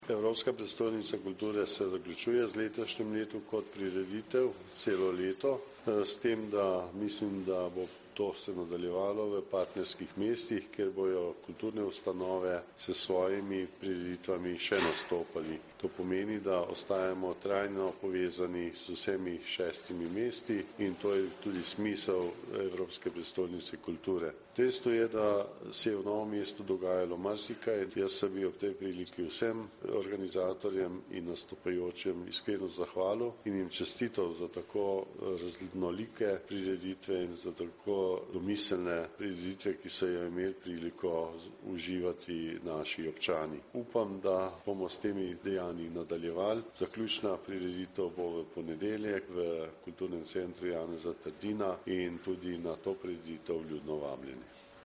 Župan Alojzij Muhič o celoletnem projektu Evropska prestolnica kulture 2012